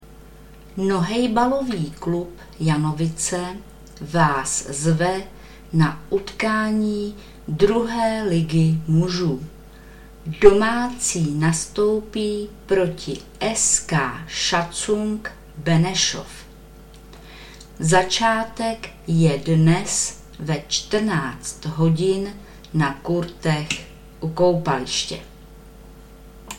Hlášení místního rozhlasu - Nohejbalové utkání v Janovicích dne 30.8.2025